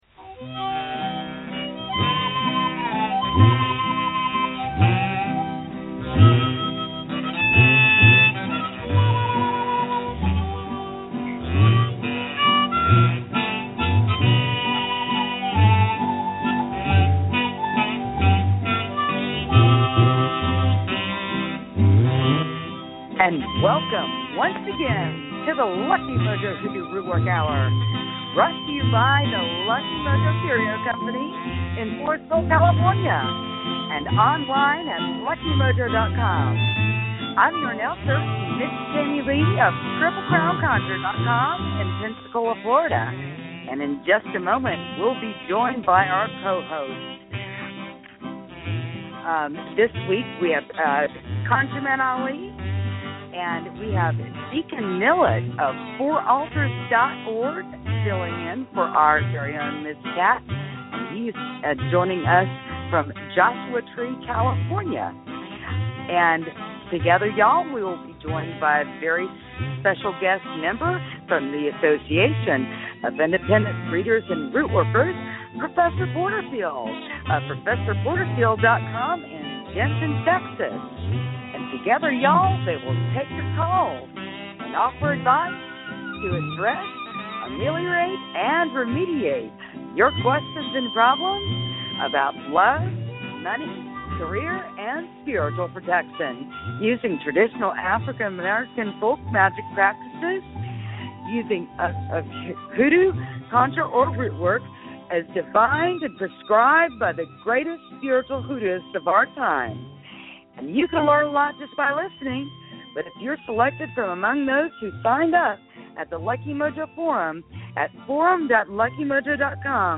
co-hosts
teach a lesson on Dowsing and Doodlebugging and then provide free readings, free spells, and conjure consultations, giving listeners an education in African-American folk magic.